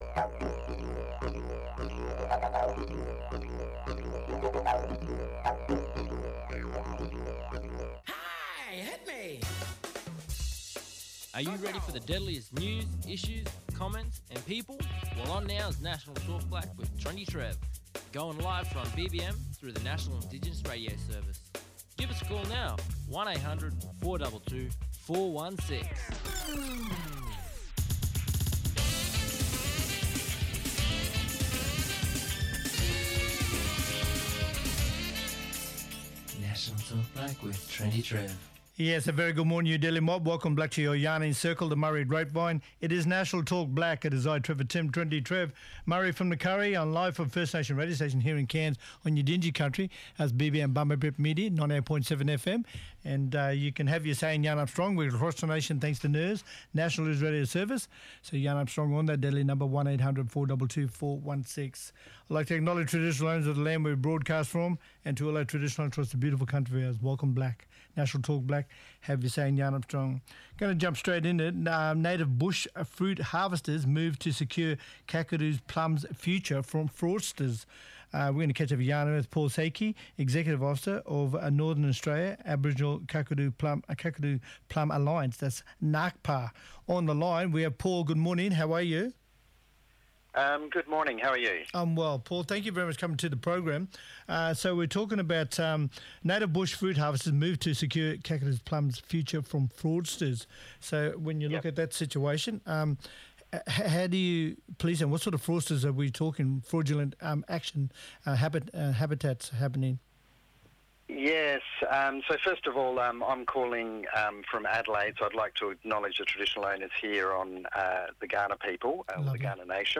Wesley Enoch, Playwright and Artistic director talking about First XI of 1868: The story of the all-Aboriginal cricket side that was Australia’s first sports team to tour overseas.